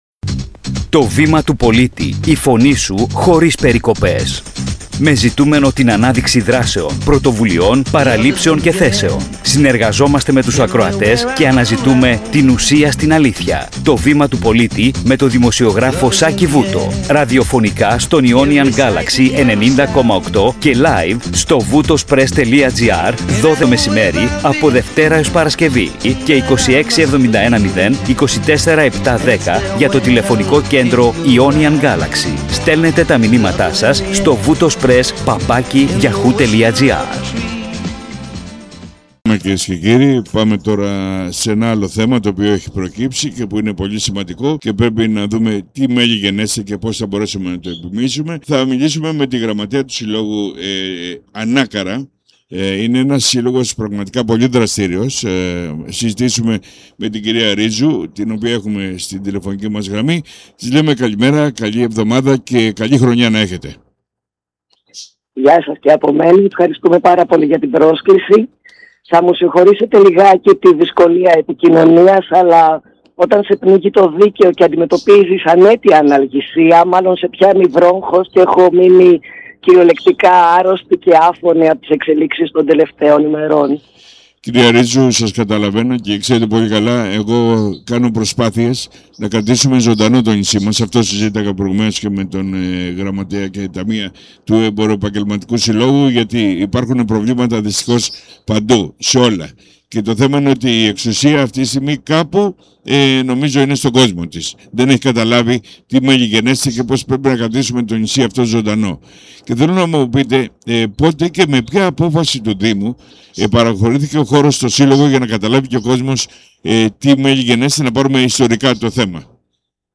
Η αιτιολογία από πλευράς Δήμου (όπως μεταφέρεται στη συνέντευξη) είναι ότι ο χώρος χρειάζεται για το υπό ίδρυση Μουσικό Γυμνάσιο .